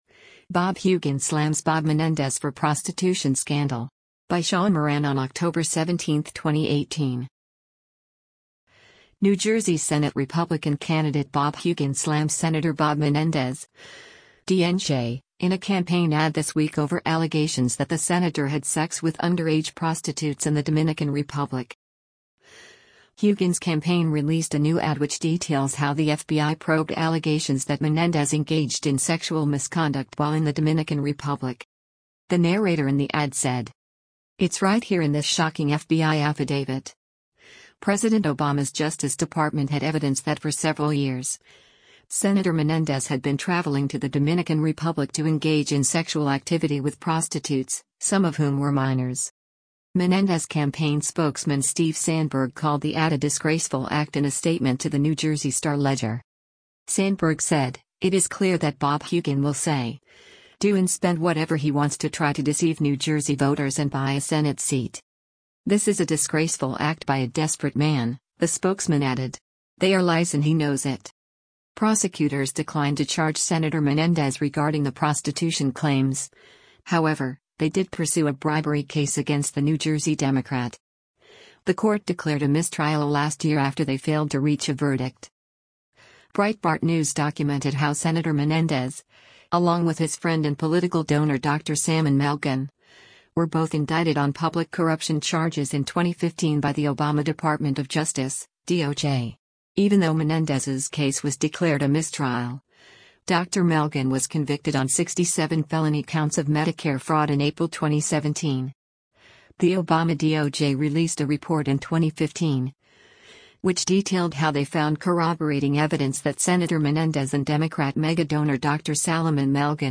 The narrator in the ad said: